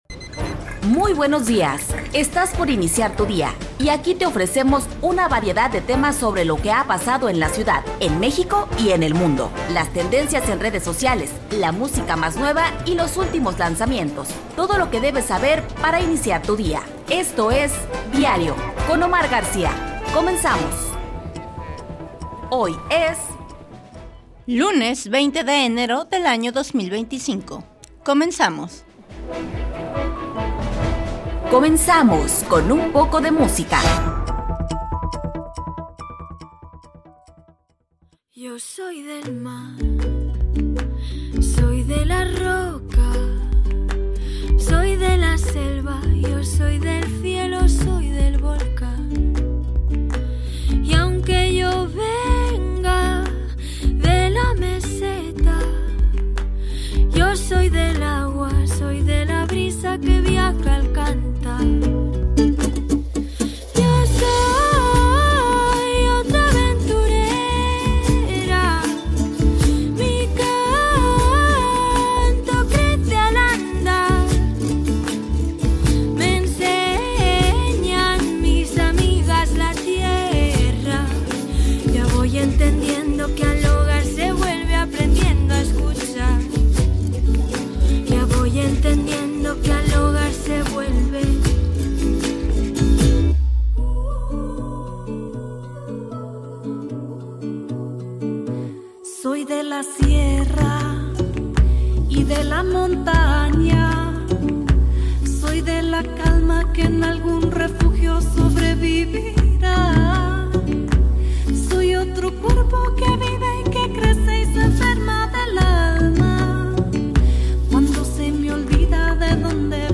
Revista Informativa de Radio Universidad de Guadalajara